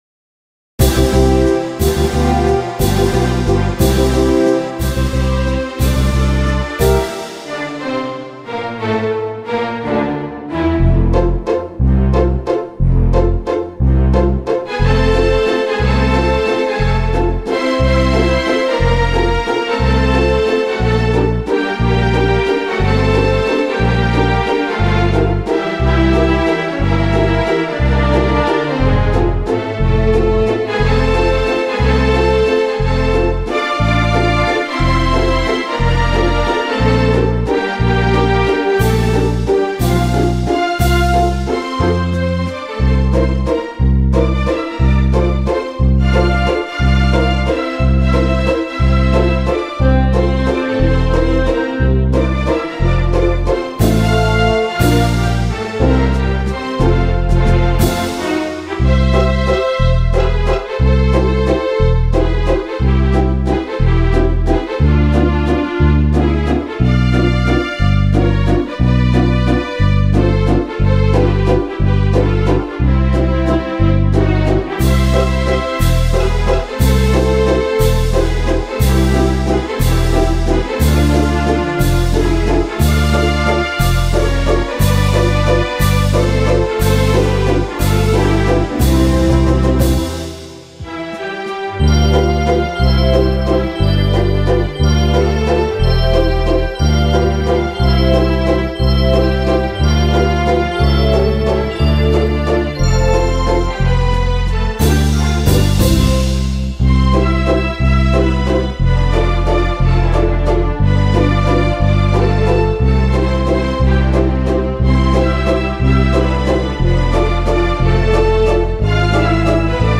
JAAkDWDkKMu_valse-de-vienne-medley-01.mp3